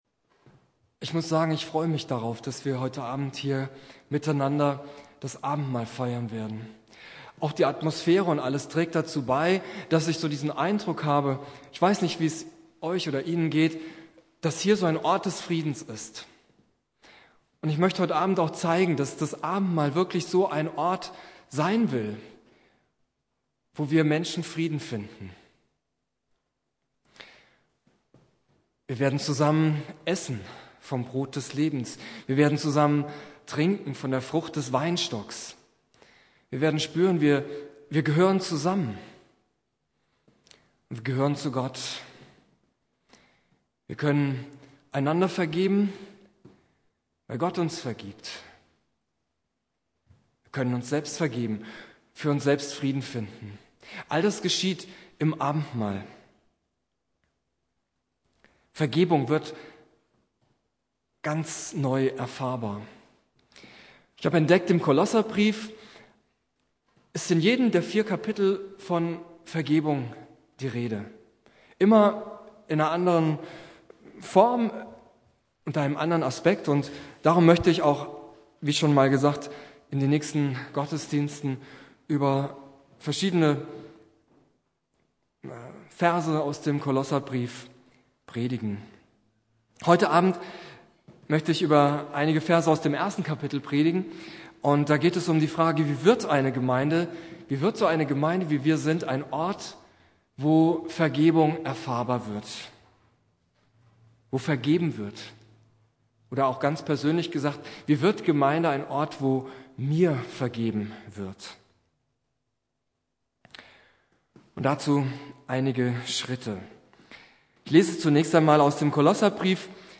Predigt
Gründonnerstag Prediger